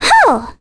Requina-Vox_Attack4.wav